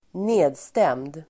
Uttal: [²n'e:dstem:d]